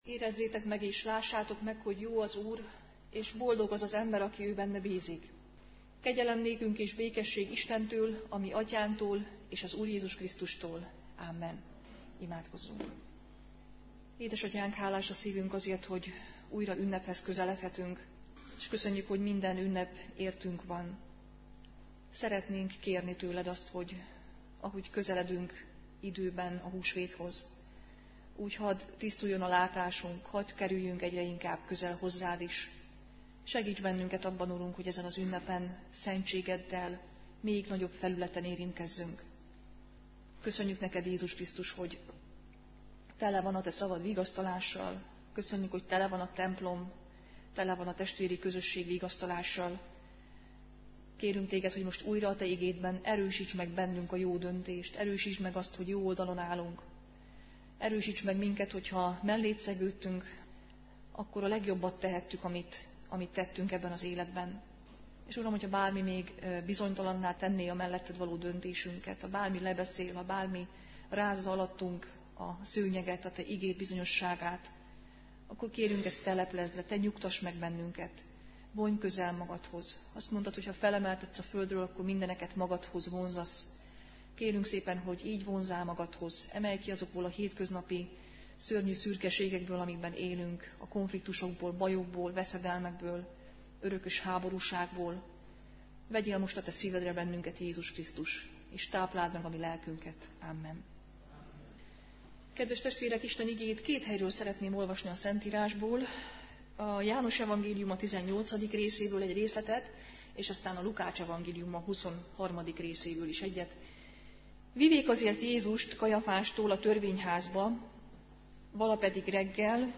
2010 március 31, szerda délután; bűnbánati Istentisztelet